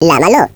Add hebrew voices